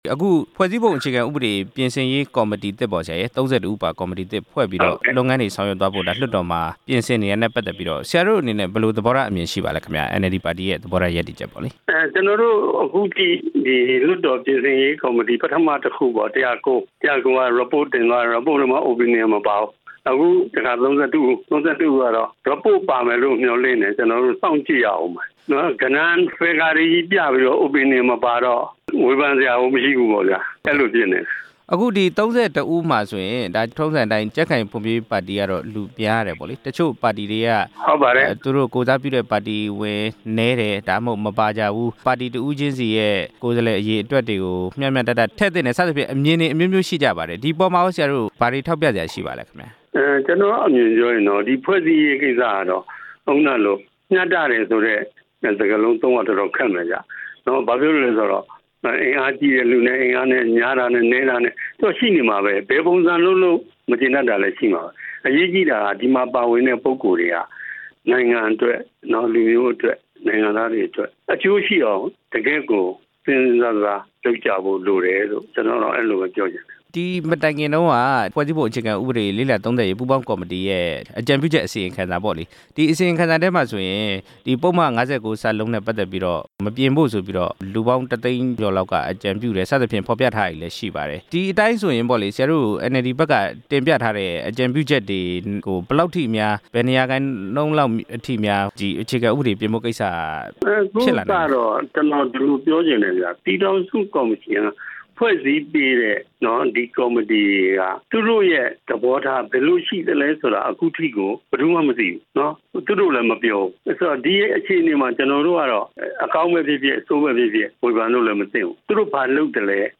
ဖွဲ့စည်းပုံ အခြေခံဥပဒေပြင်ဆင်ရေး ကော်မတီသစ်အကြောင်း မေးမြန်းချက်